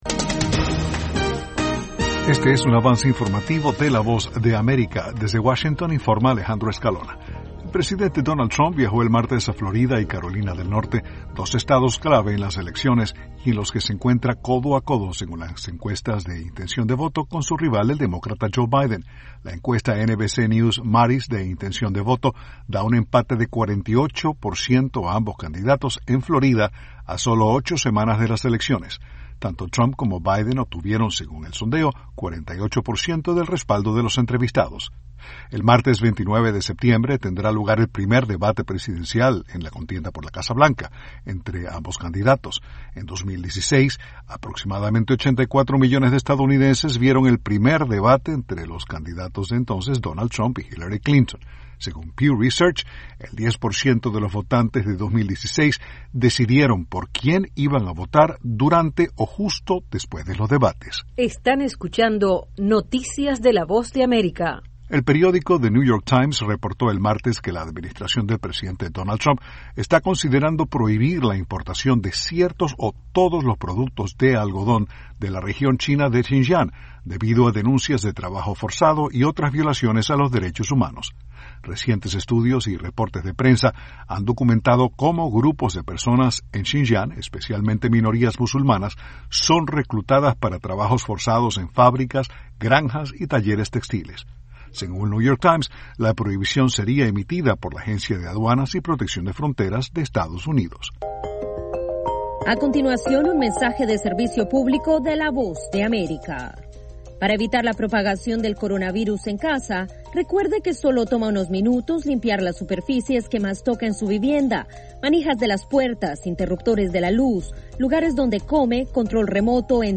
Avance Informativo 6:00pm